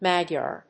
音節Mag・yar 発音記号・読み方
/mˈægjɑɚ(米国英語), ˈmæˌgjɑ:r(英国英語)/